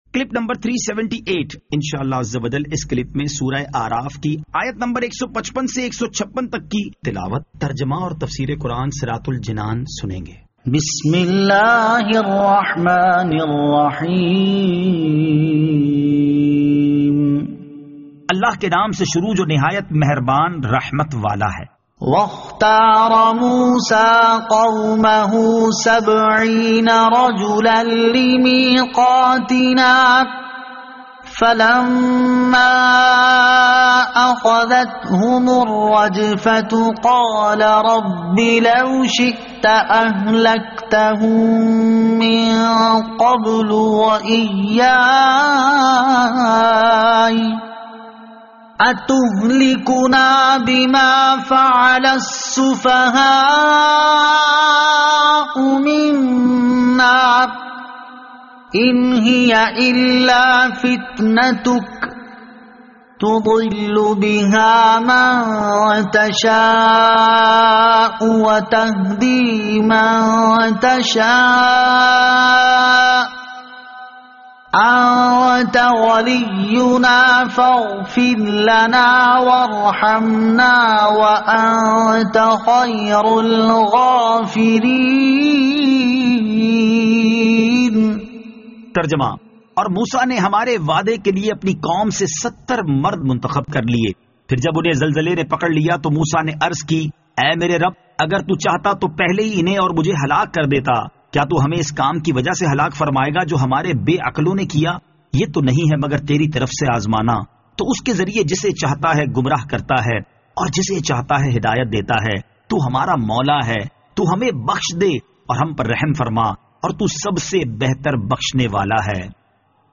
Surah Al-A'raf Ayat 155 To 156 Tilawat , Tarjama , Tafseer